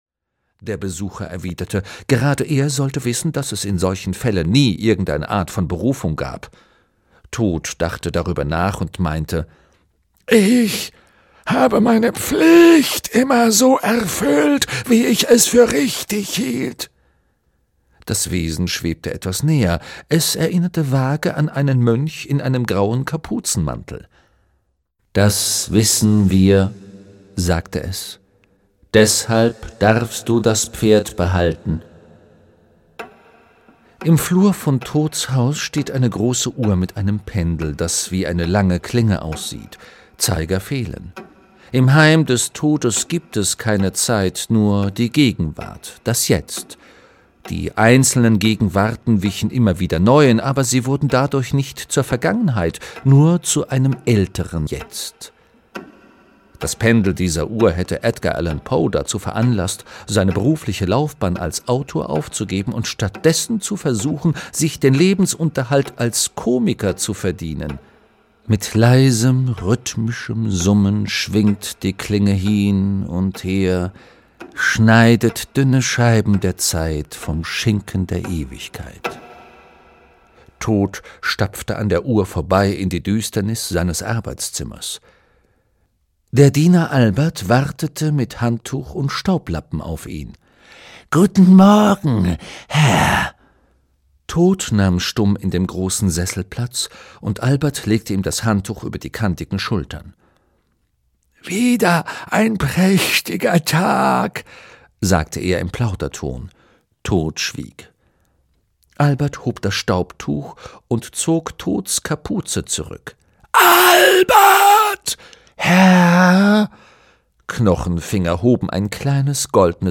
Audio kniha
Ukázka z knihy
• InterpretRufus Beck